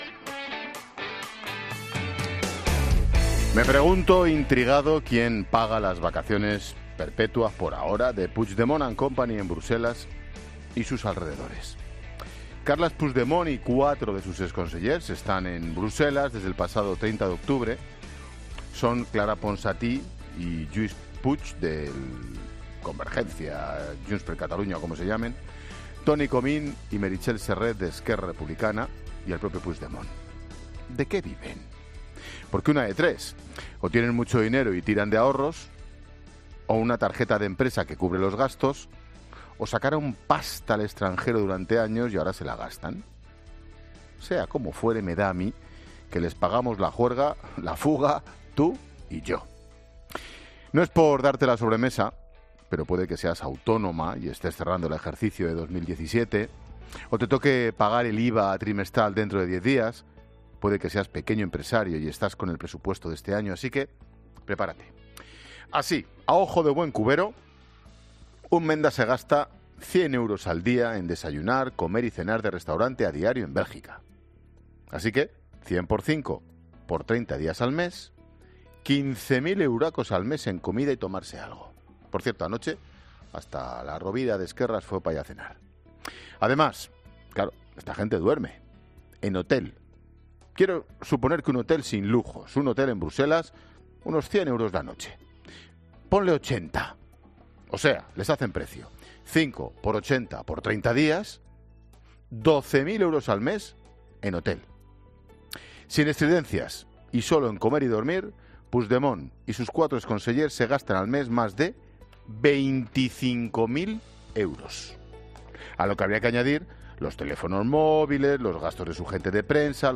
AUDIO: El comentario de Ángel Expósito sobre las vacaciones de Puidgemont y compañía en Bruselas